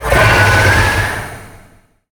Sfx_creature_shadowleviathan_chitter_02.ogg